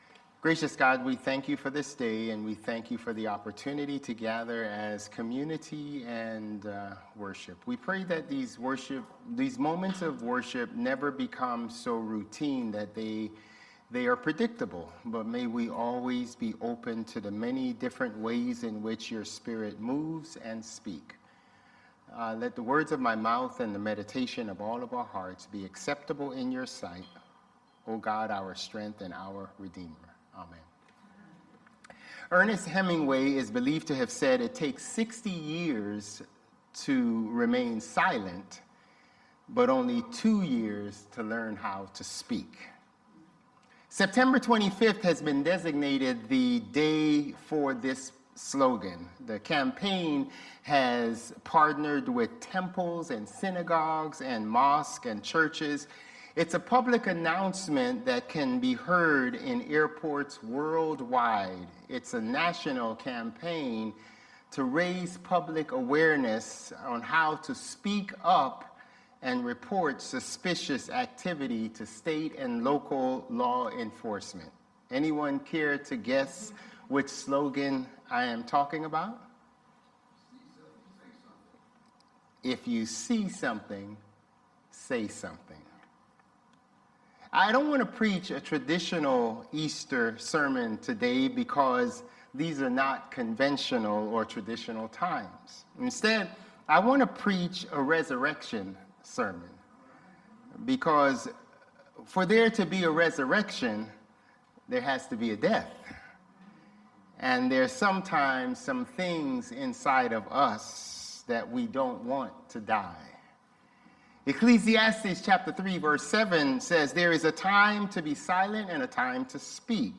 Sermons | Bethel Lutheran Church
April 20 Worship - Easter 2025